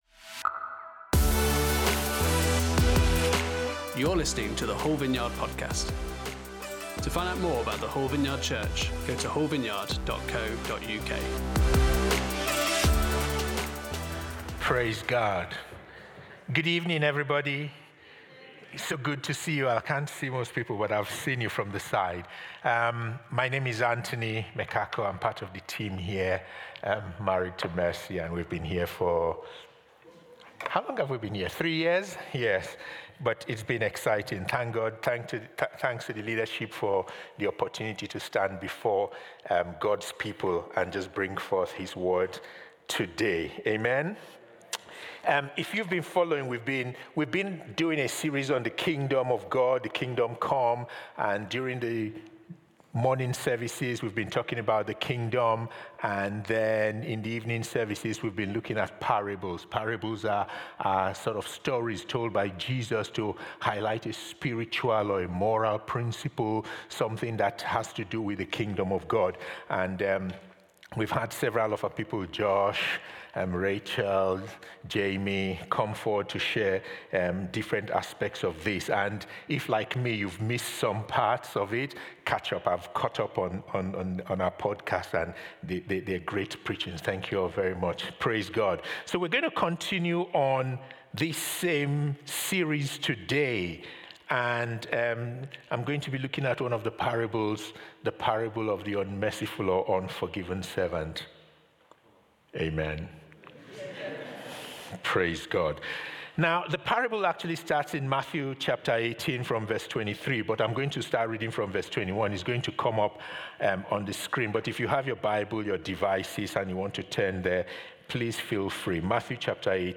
Parable Service Type: Sunday Service On Sunday evening